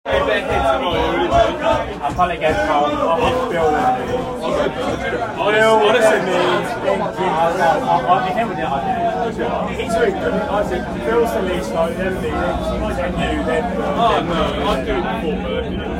오늘은 베를린에서 스페인과 잉글랜드의 유로 결승 경기가 열리는 날이라 기차 안에서 스페인 사람들이 엄청나게 과열되어 있었고, 그 장면이 다소 생경하고도 흥미로웠다. 좁은 기차칸 안에서 그들의 신선한 열기를 소리만 담아보기도 했다.
유로 결승날 베를린의 지하철.m4a